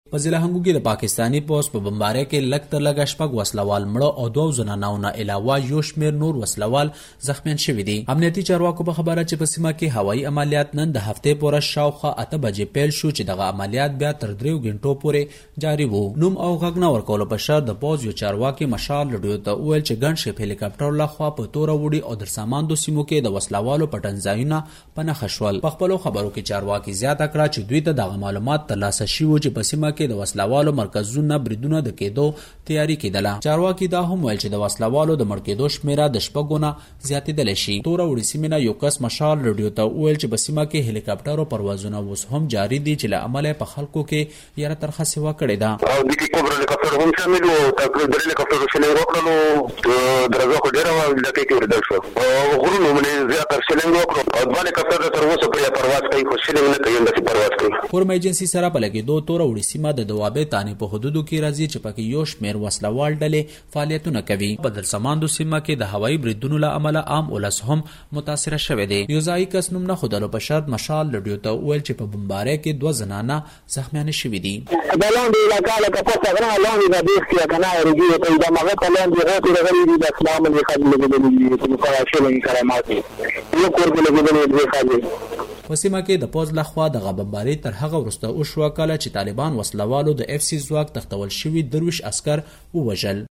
د هنګو د پېښې په اړه رپوټ دلته واورئ